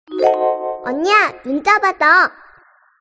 Nada notifikasi Unni, Anda Memiliki Pesan versi Korea
Kategori: Nada dering
nada-notifikasi-unni-anda-memiliki-pesan-versi-korea-id-www_tiengdong_com.mp3